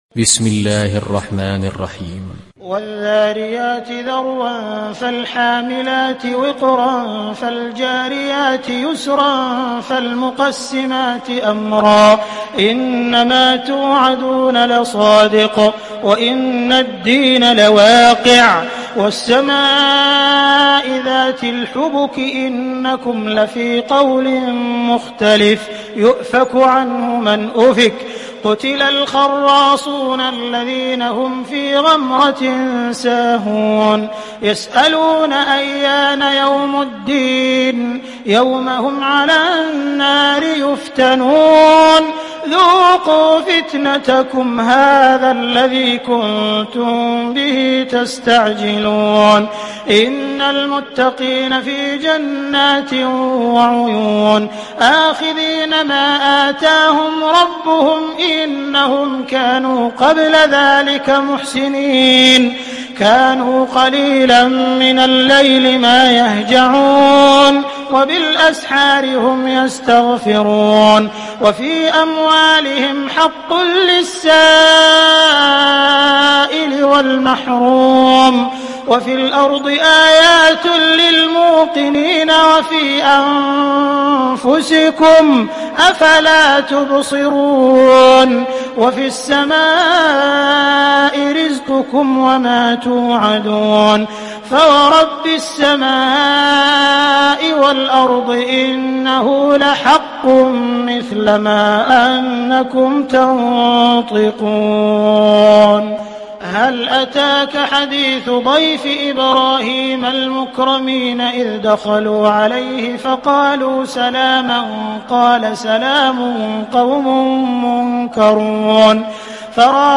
Surah Ad Dariyat Download mp3 Abdul Rahman Al Sudais Riwayat Hafs from Asim, Download Quran and listen mp3 full direct links